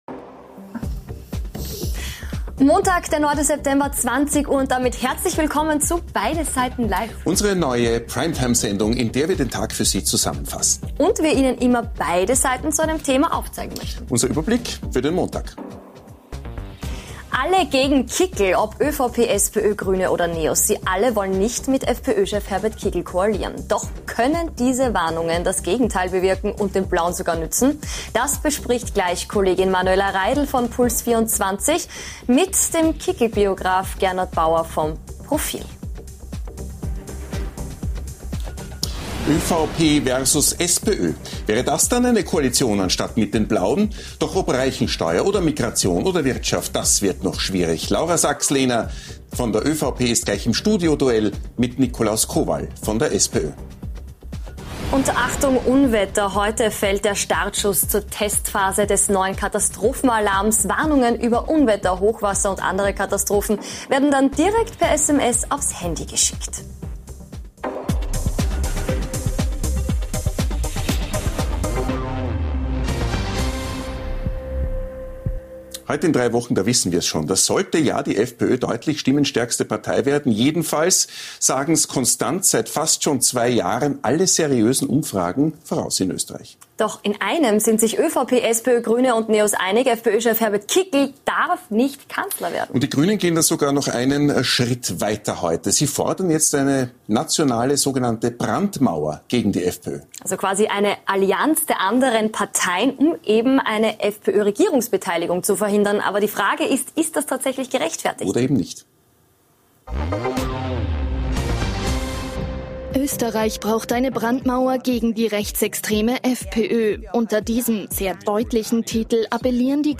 Und nachgefragt haben wir heute bei gleich zwei Gästen - im großen Beide Seiten Live Duell mit Laura Sachslehner, ÖVP und Nikolaus Kowall, SPÖ.